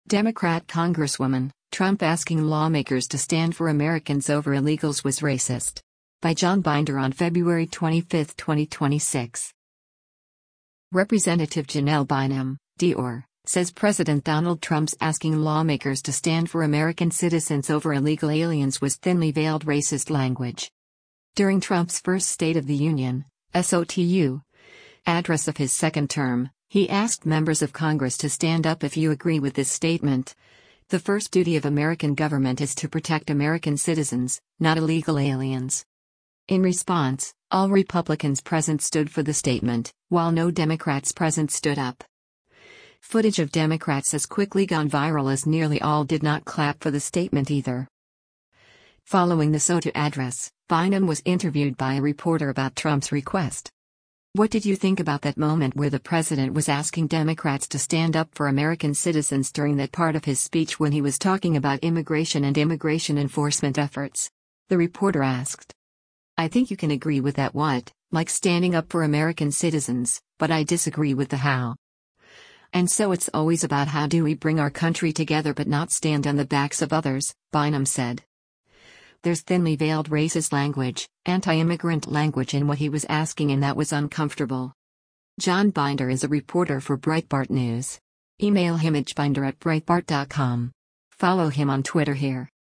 Following the SOTU address, Bynum was interviewed by a reporter about Trump’s request.